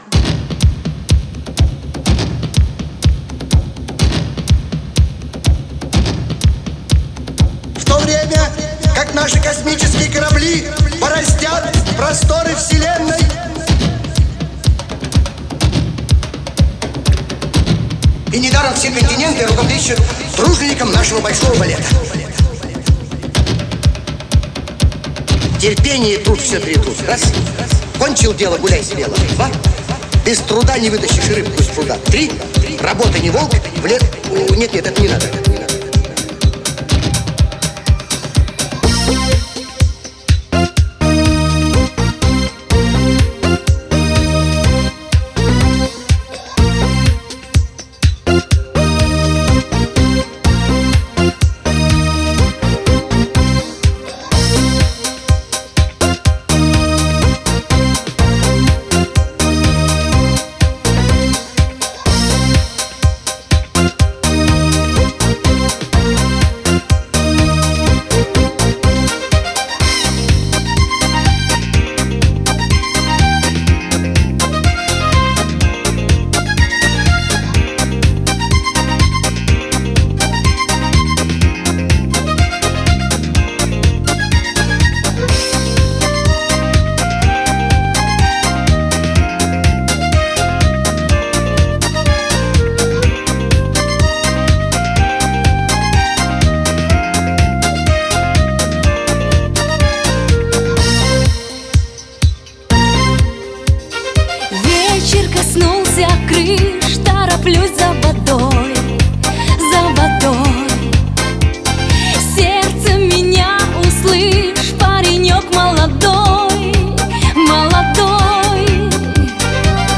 вокал
вокал, бас-гитара, клавишные
альт-саксофон, клавишные
ударные, перкуссия